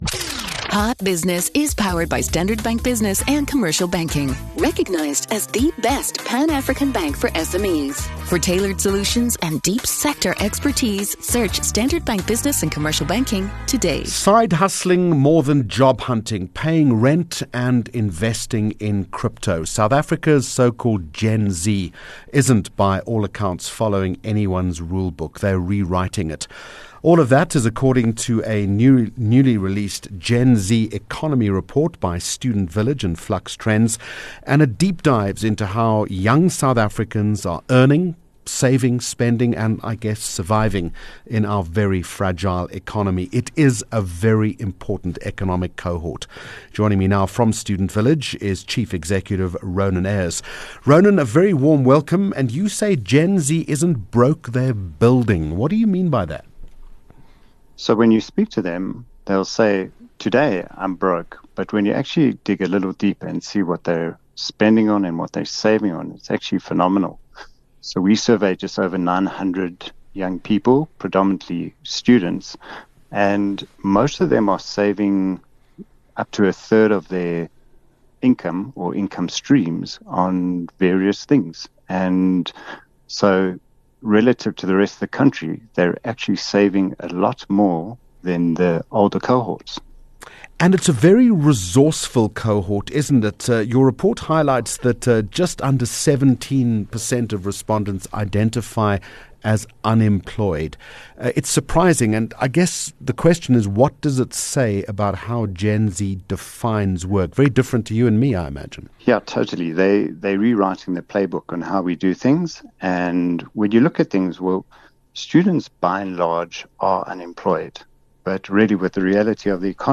17 Jun Hot Business Interview